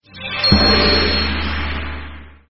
youwin.mp3